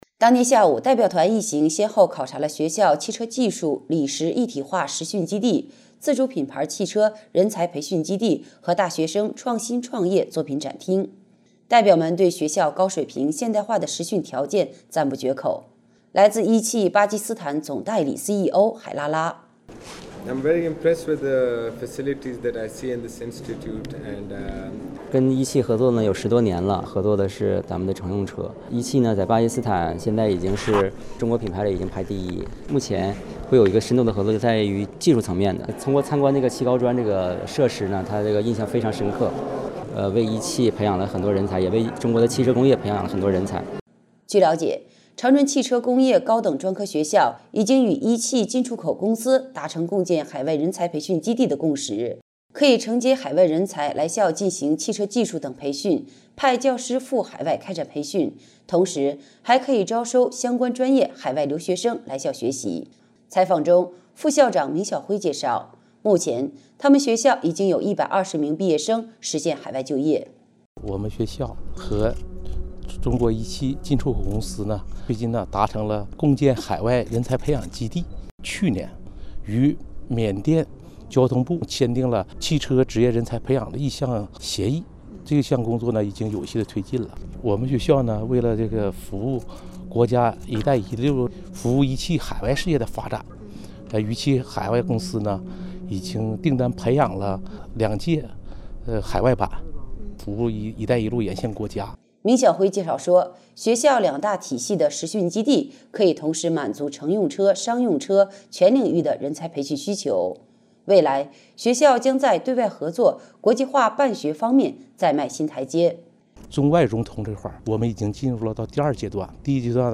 【广播录音文字稿】